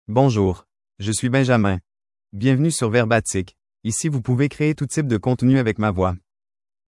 Benjamin — Male French (Canada) AI Voice | TTS, Voice Cloning & Video | Verbatik AI
Benjamin is a male AI voice for French (Canada).
Voice sample
Listen to Benjamin's male French voice.
Benjamin delivers clear pronunciation with authentic Canada French intonation, making your content sound professionally produced.